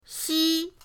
xi1.mp3